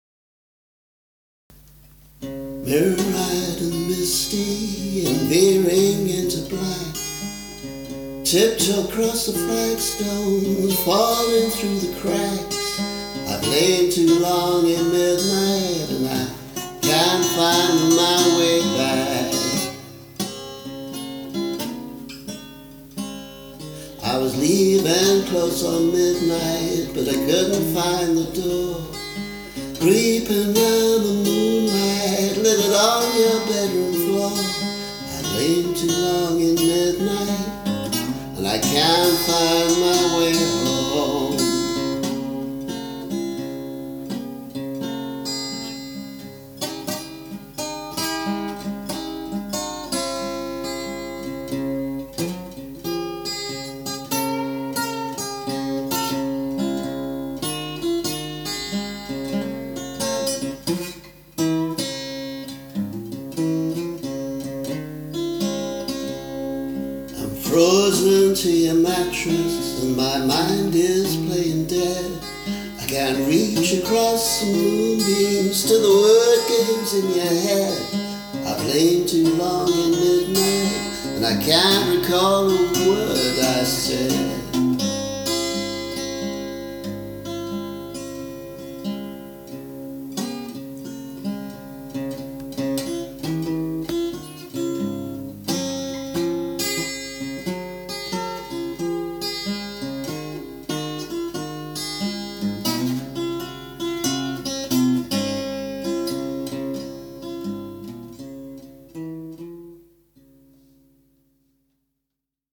moonstruck [demo]